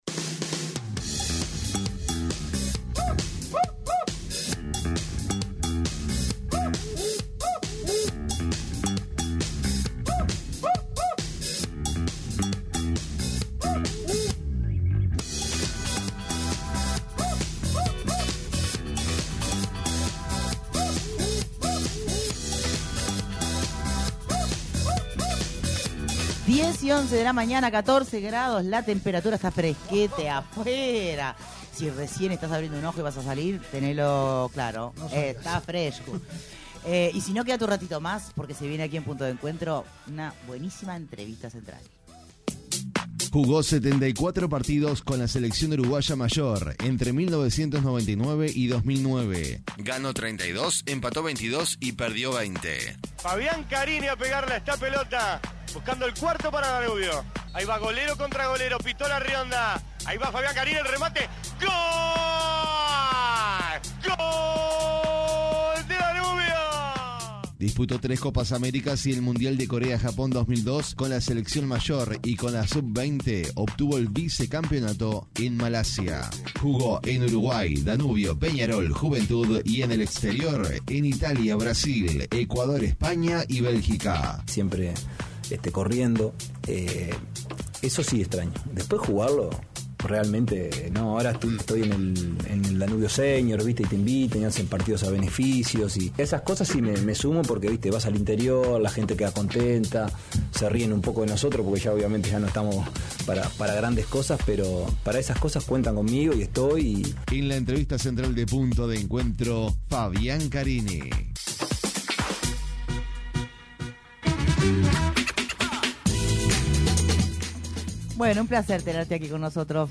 ENTREVISTA: FABIÁN CARINI